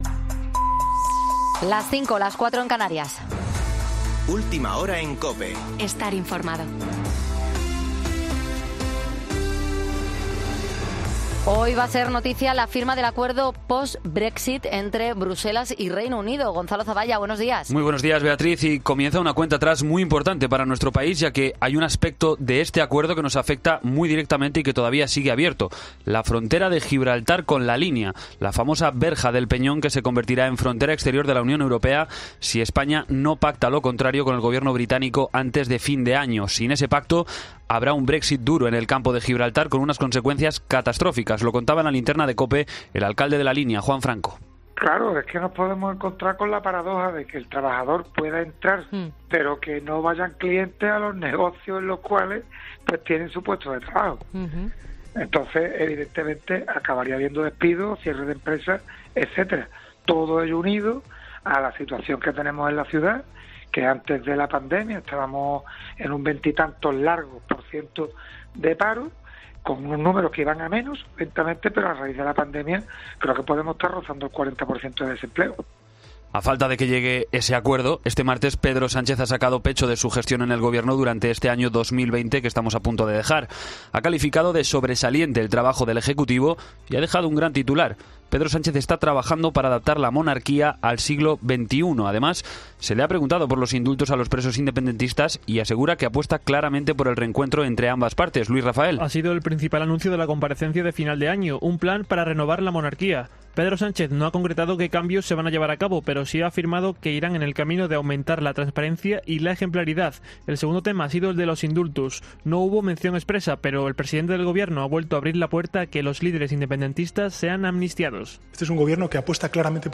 Boletín de noticias COPE del 30 de diciembre de 2020 a las 05.00 horas